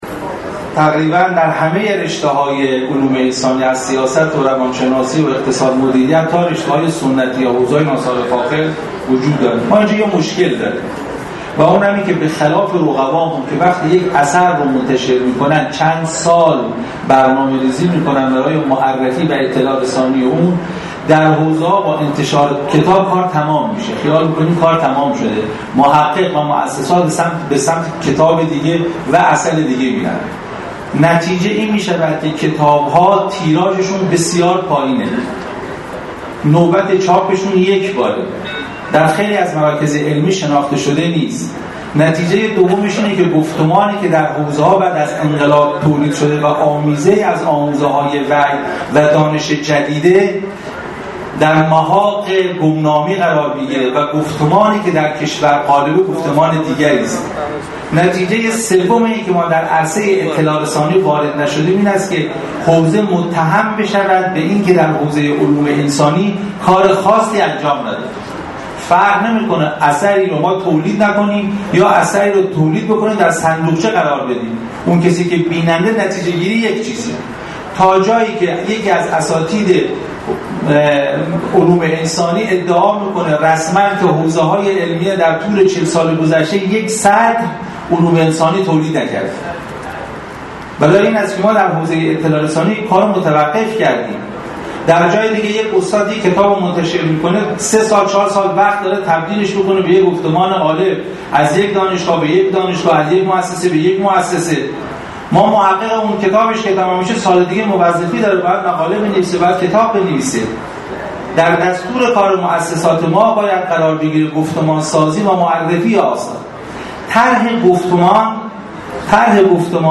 در مراسم اختتامیه نمایشگاه تخصصی گفتمان علمی انقلاب اسلامی که امروز در سالن همایش های غدیر برگزار شد